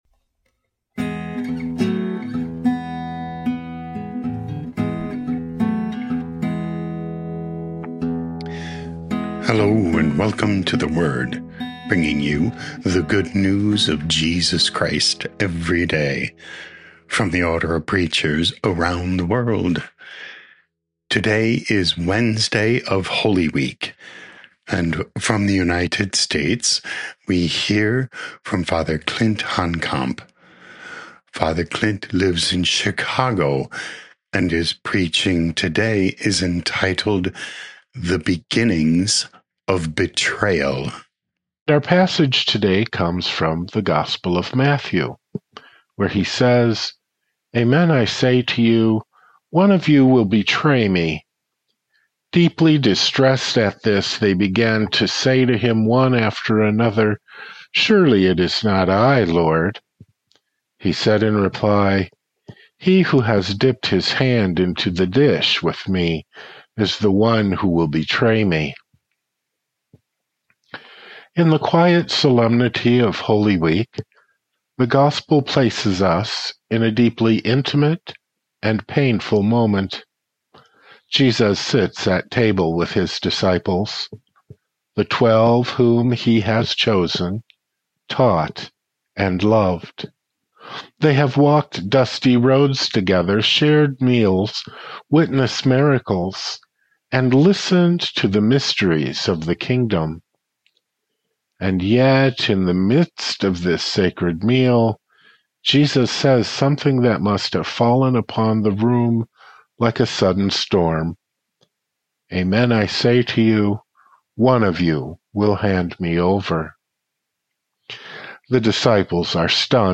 theWord – daily homilies from the Order of Preachers